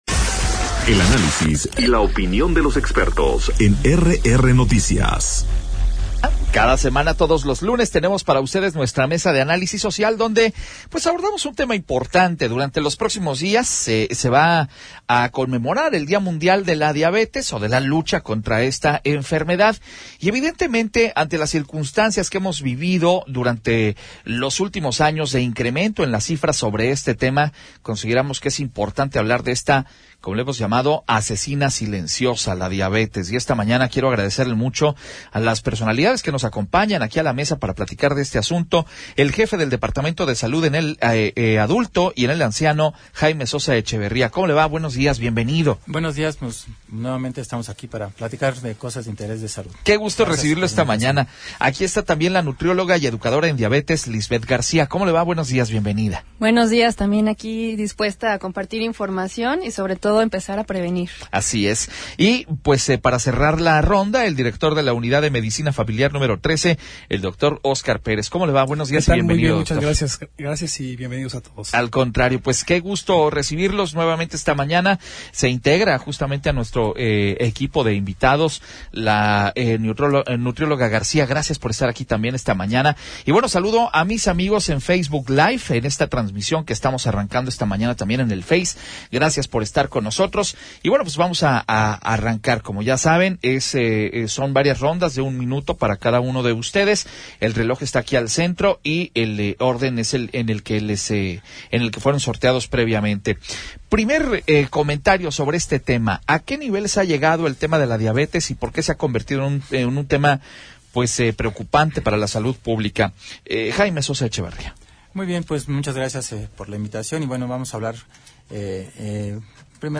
Mesa de Análisis: Diabetes, asesina silenciosa - RR Noticias